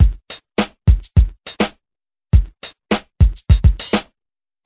• 103 Bpm Drum Loop Sample G Key.wav
Free drum beat - kick tuned to the G note. Loudest frequency: 961Hz
103-bpm-drum-loop-sample-g-key-eXv.wav